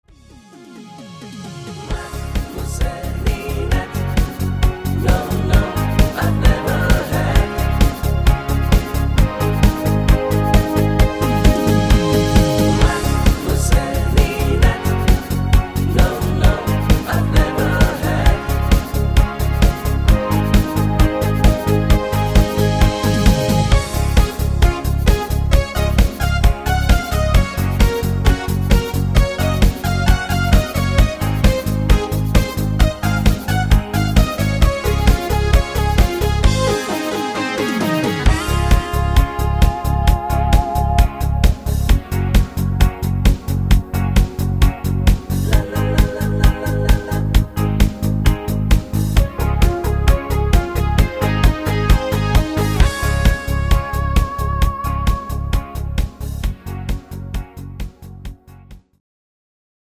neue Discofox Version engl.